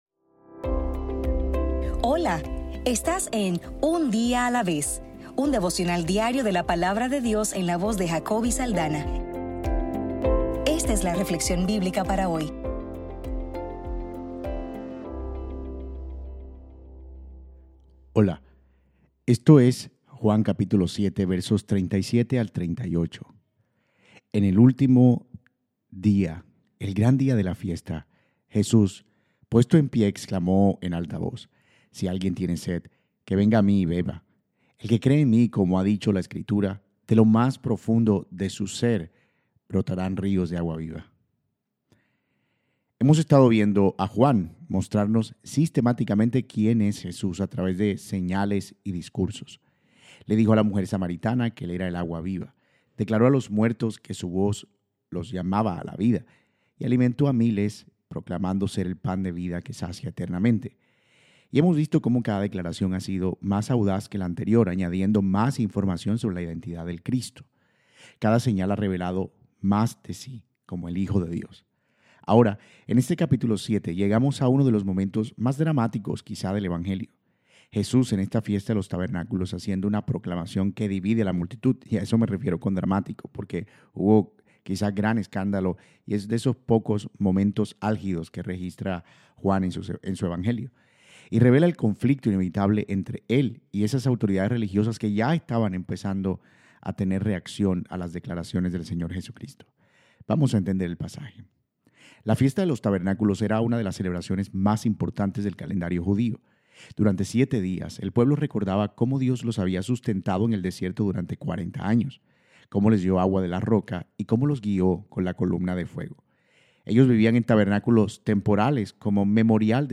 Devocional para el 17 de Diciembre